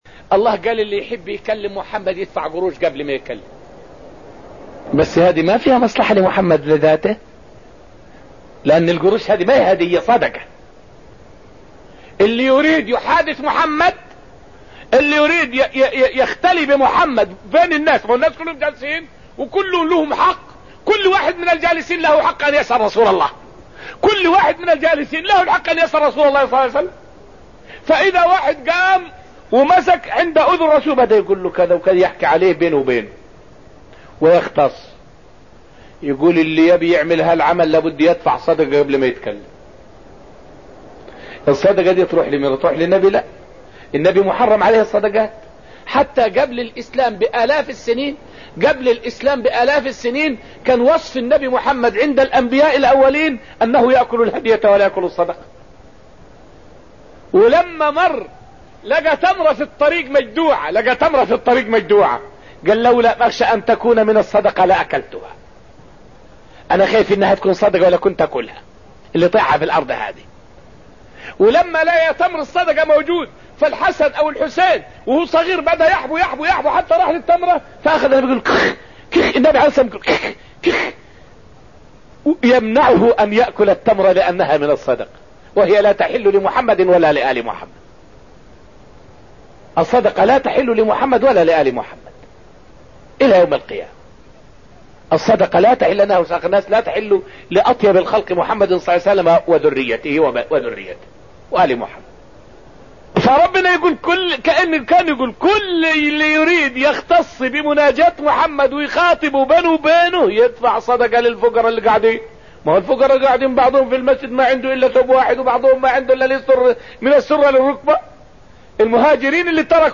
فائدة من الدرس التاسع من دروس تفسير سورة المجادلة والتي ألقيت في المسجد النبوي الشريف حول آية {فقدموا بين يدي نجواكم صدقة}.